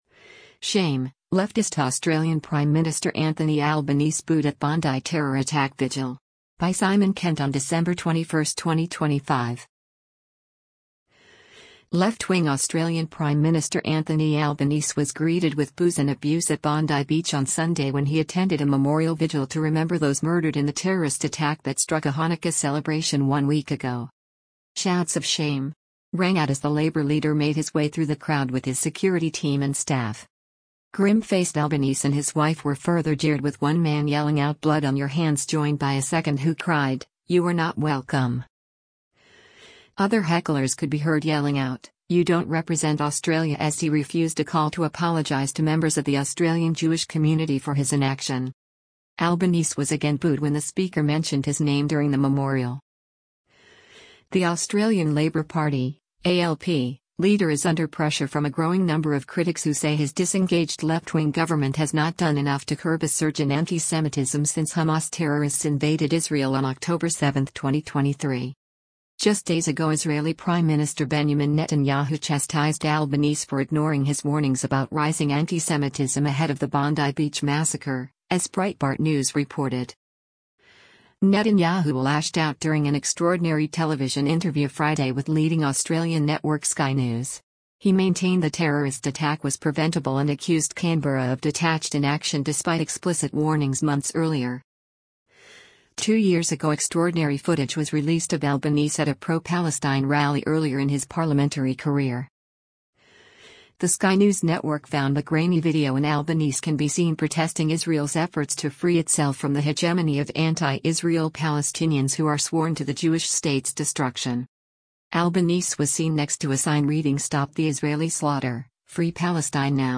Left-wing Australian Prime Minister Anthony Albanese was greeted with boos and abuse at Bondi Beach on Sunday when he attended a memorial vigil to remember those murdered in the terrorist attack that struck a Hanukkah celebration one week ago.
Shouts of “shame!” rang out as the Labor leader made his way through the crowd with his security team and staff.
Grim-faced Albanese and his wife were further jeered with one man yelling out “blood on your hands” joined by a second who cried, “you are not welcome.”
Other hecklers could be heard yelling out, “you don’t represent Australia” as he refused a call to apologise to members of the Australian Jewish community for his inaction.
Albanese was again booed when the speaker mentioned his name during the memorial.